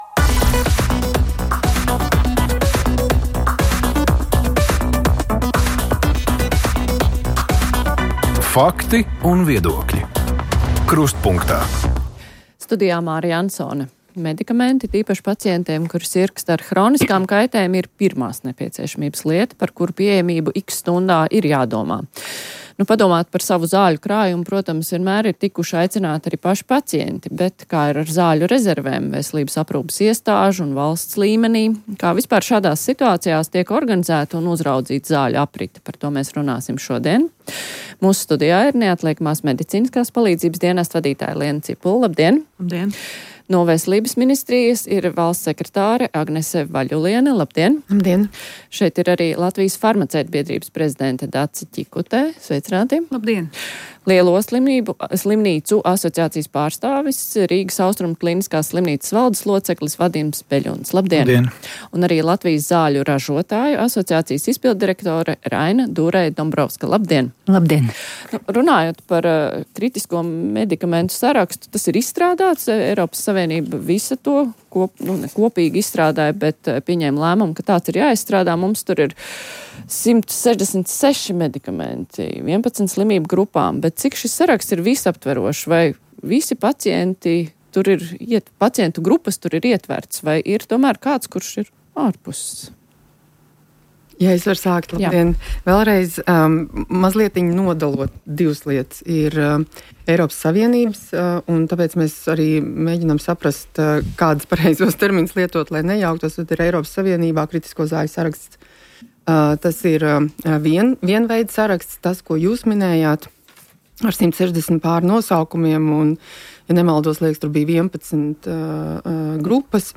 Mūsu redzes lokā aktuālo notikumu analīze Latvijā un pasaulē: politiskās diskusijas, ekonomikas, sociālo u.c. problēmu analīze, amatpersonu izvaicāšana, pētnieciskie raidījumi. Tāpat studijā kopā ar ekspertiem izvērtējam un komentējam politiskās, ekonomiskās un sociālās norises Latvijā.